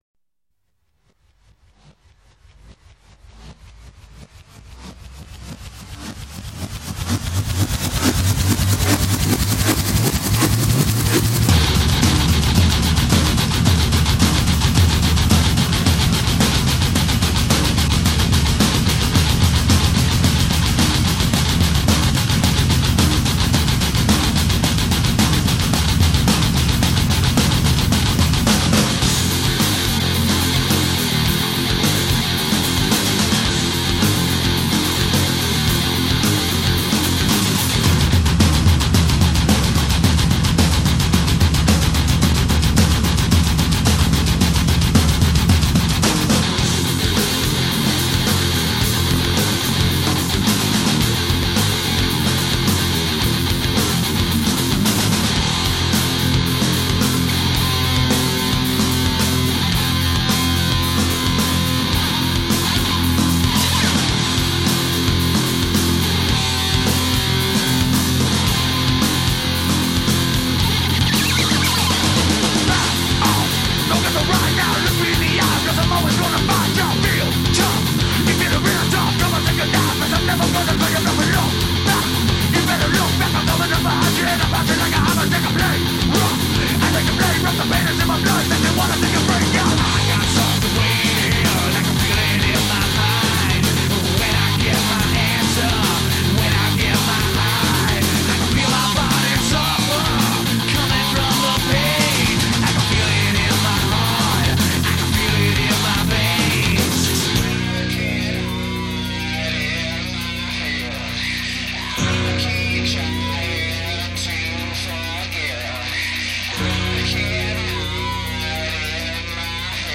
2.7 megabytes - mono